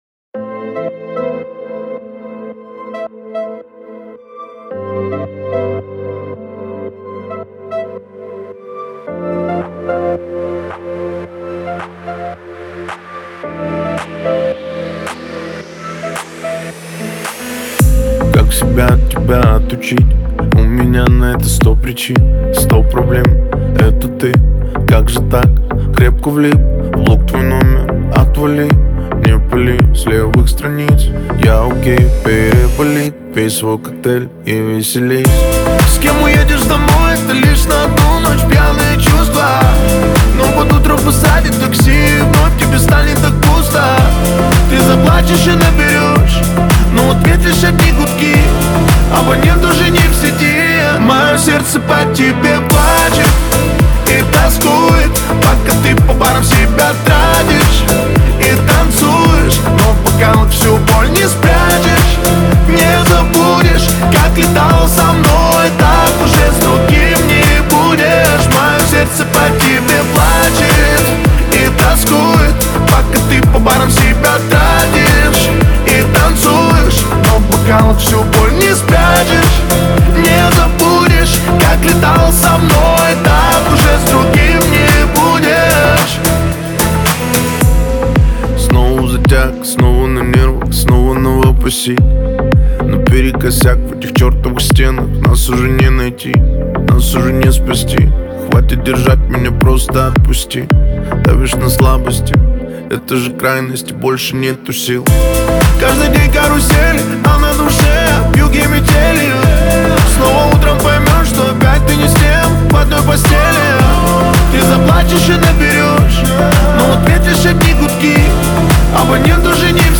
это энергичная поп-музыка с элементами хип-хопа